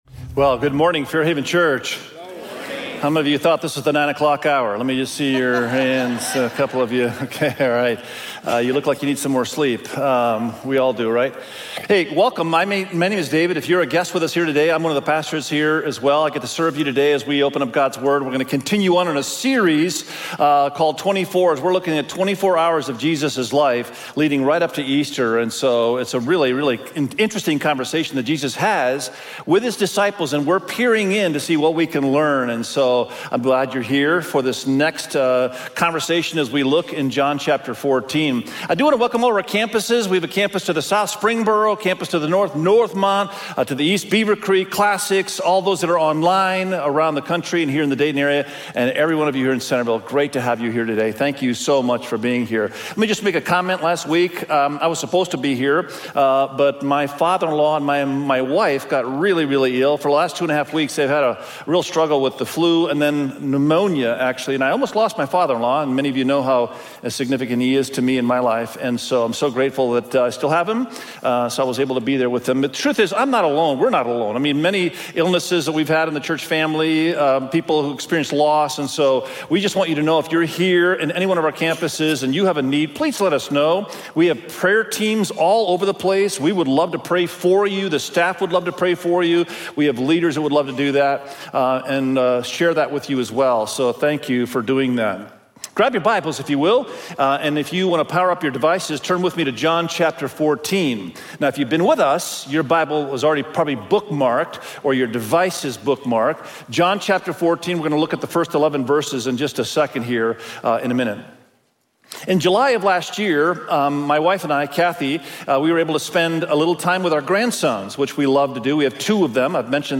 24-Hours_I-Will-Do-It_SERMON.mp3